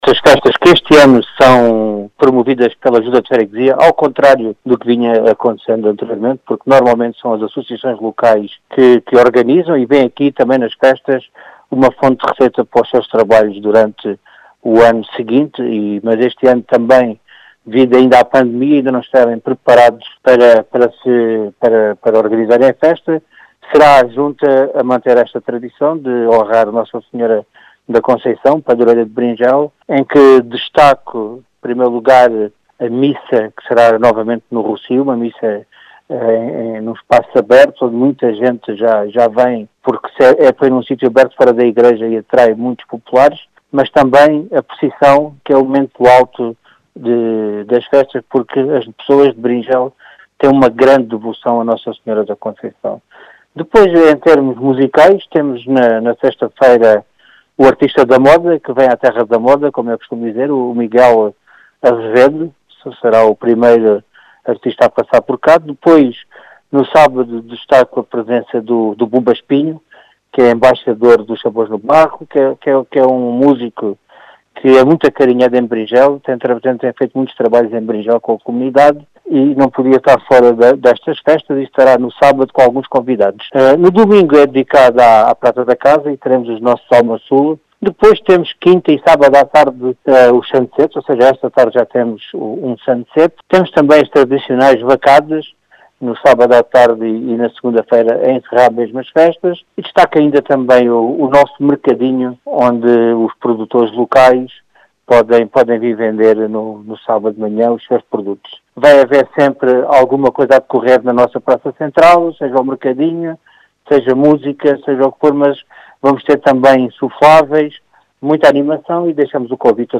As explicações são de Vitor Besugo, presidente da junta de freguesia de Beringel, que este ano organiza as festividades.